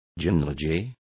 Complete with pronunciation of the translation of genealogies.